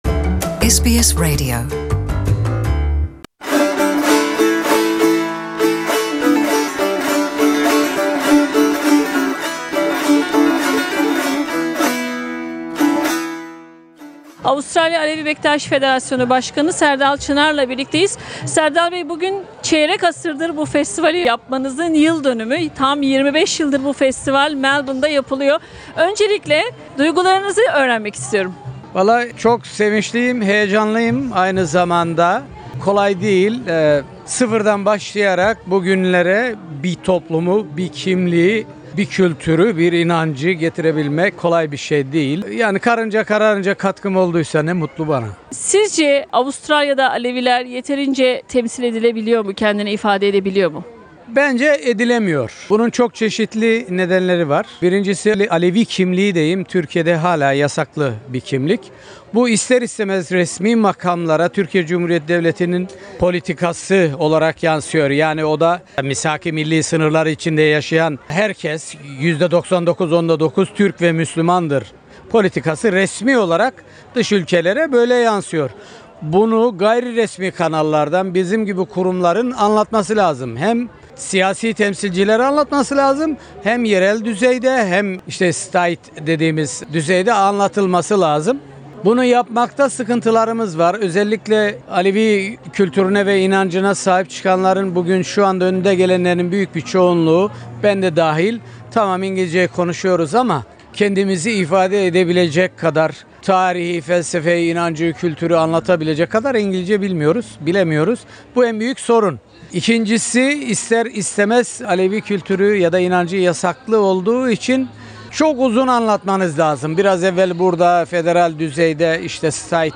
25'nci yılını kutladıkları Anadolu Alevi Festivali'nde SBS Radyosu Türkçe programı'na, Avustralya'daki Alevilerin genel durumunu değerlendirdi.